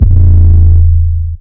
BWB WAV 7 808 (1).wav